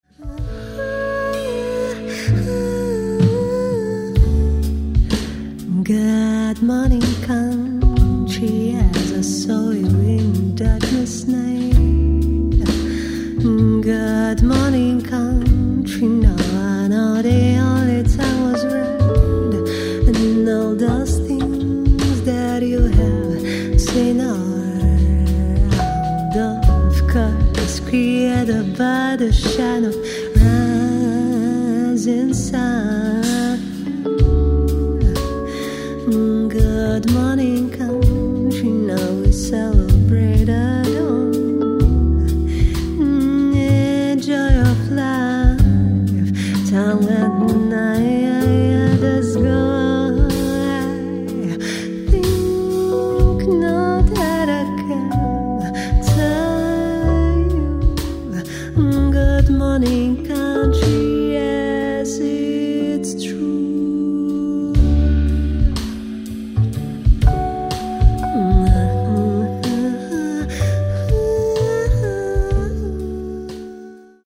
Каталог -> Джаз и около -> Джаз-вокал
vocals
trumpet
keys
doublebass
drums